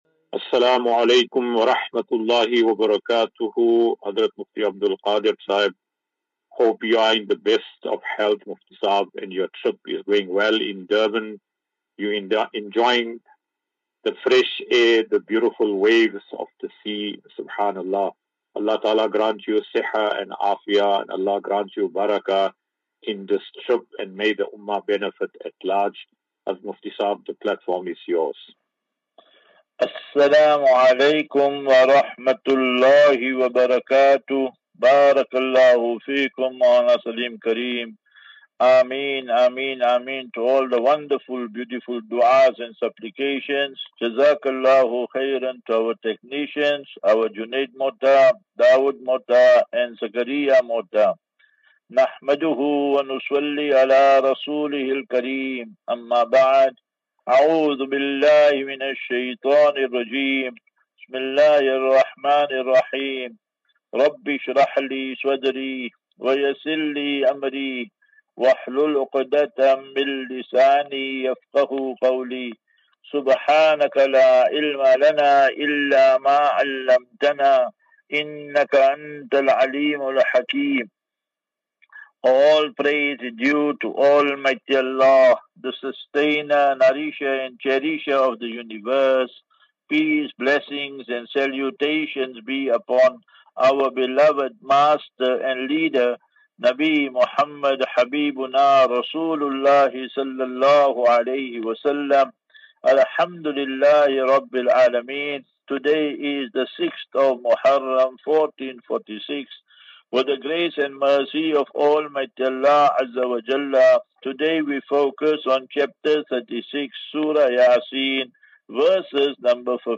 13 Jul 13 July 2024. Assfinatu - Illal - Jannah. QnA